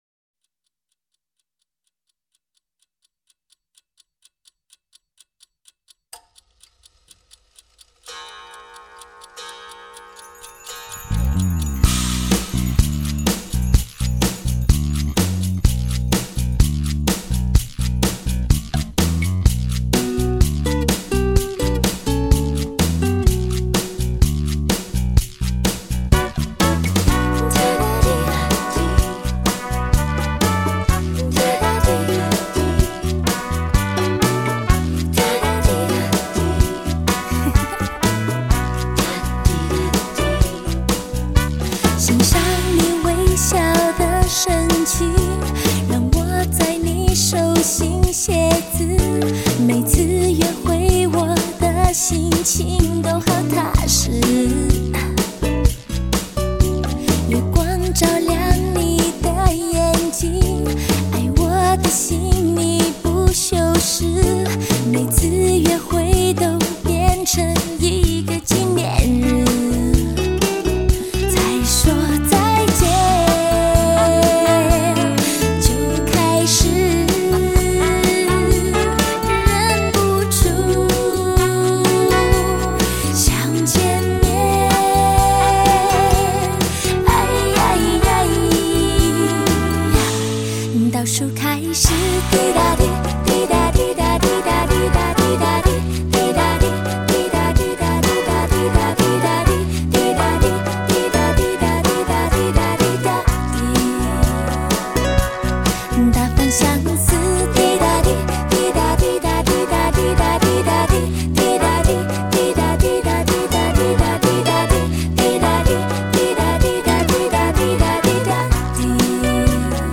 凡人最无法挡的甜蜜3D情歌